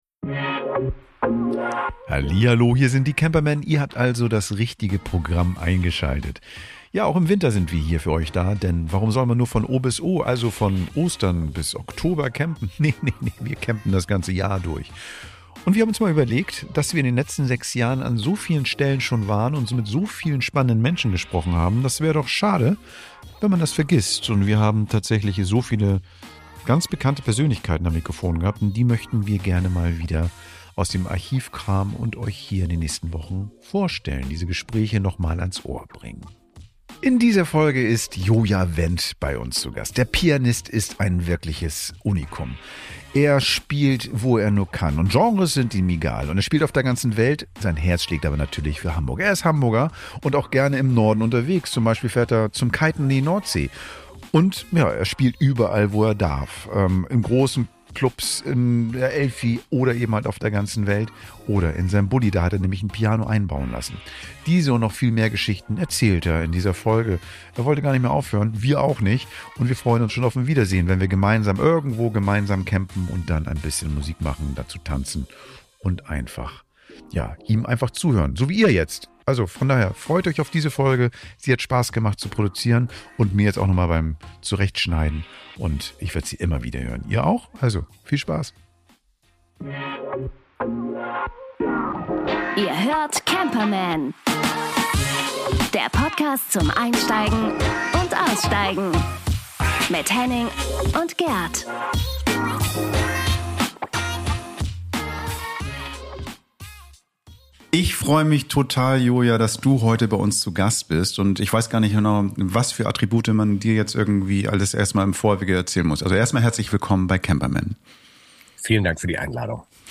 In dieser Folge unserer Artist-Specials veröffentlichen wir unser Interview mit dem Pianisten Jona Wendt. Er spricht über seinen Van mit einem eingebauten Piano, spntane Konzerte am Fährhafen und seine Liebe zu Wassersport und Natur.